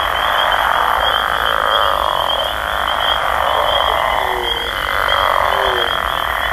Звук ночной природы.